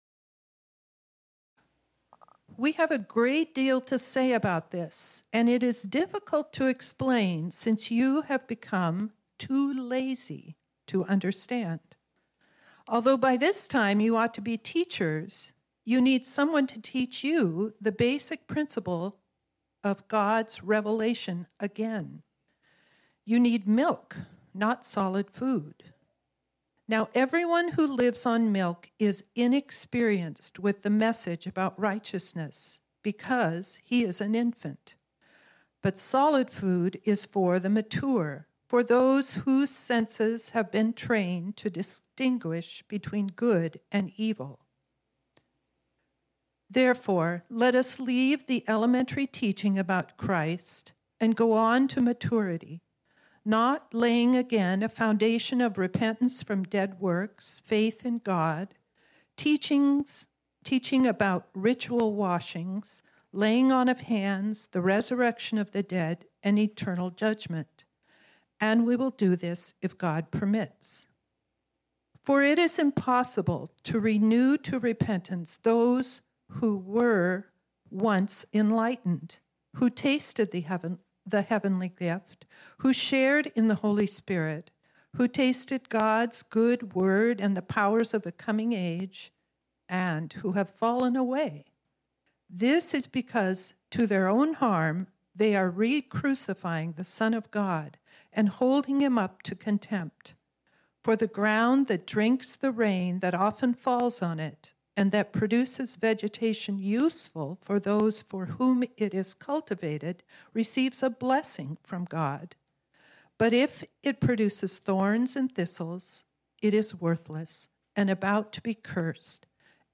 This sermon was originally preached on Sunday, November 13, 2022.